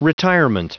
Prononciation du mot retirement en anglais (fichier audio)
Prononciation du mot : retirement